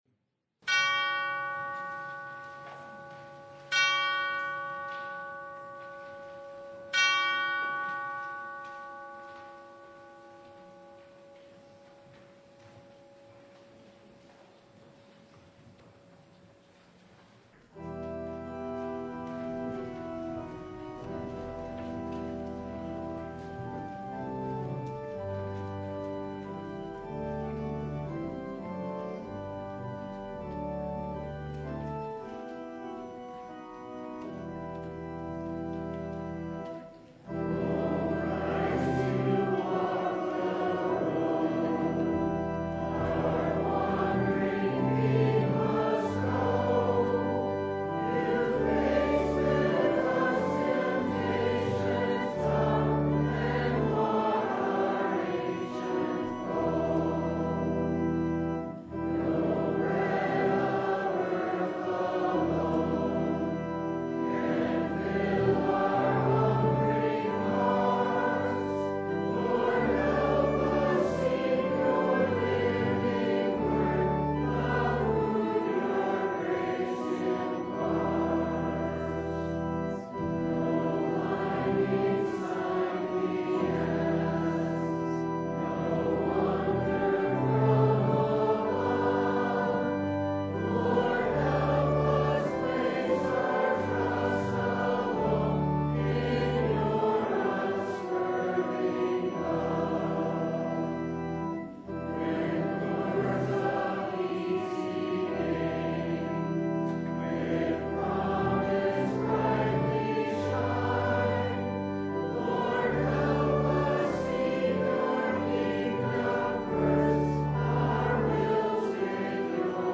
Luke 4:1-13 Service Type: Sunday Into temptation.